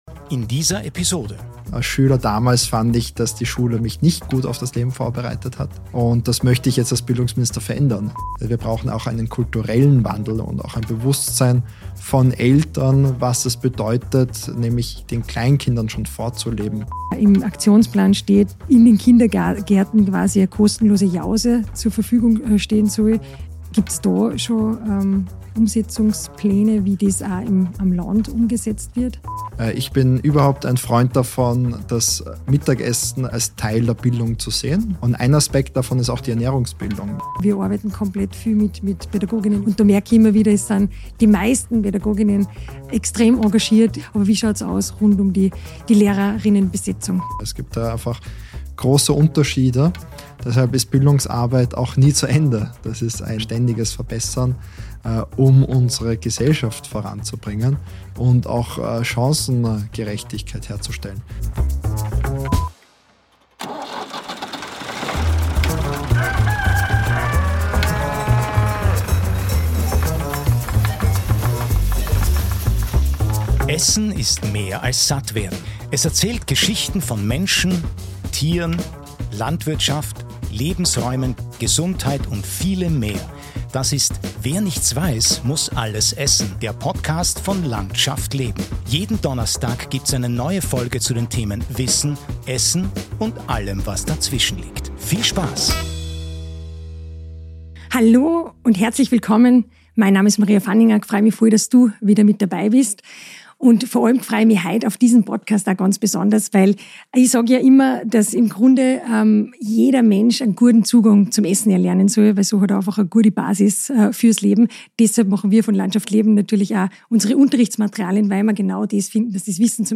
Österreichs Bundesminister für Bildung Christoph Wiederkehr
ein offenes Gespräch